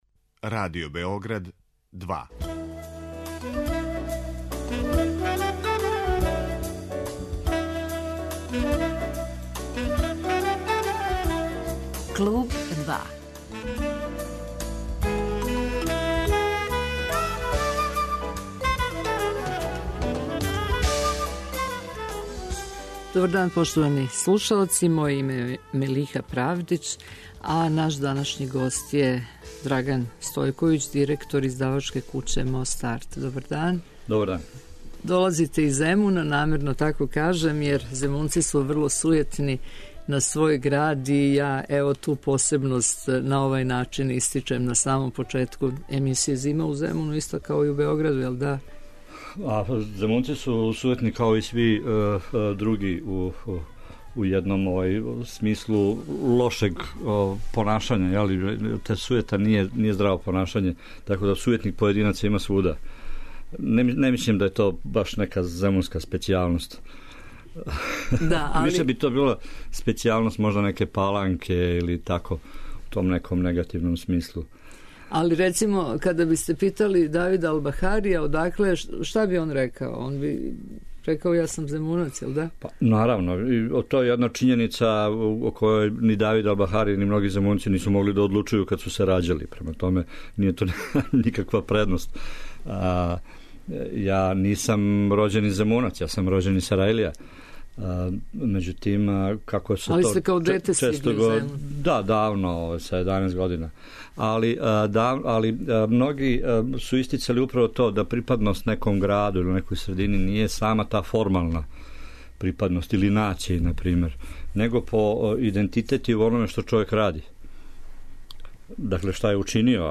У емисији ћемо представити ову издавачку кућу (њена издања и библиотеке) и указати на специфичности у погледу рада. Мост арт са једним запосленим успева да буде конкурентан издавач на тржишту књига, а модели копродукције (удруживање са другим издавачима) које примењују, дају добре резултате. Посебну пажњу у овом разговору посветићемо њиховим издањима о Земуну, у чему посебну пажњу привлаче књиге из старине.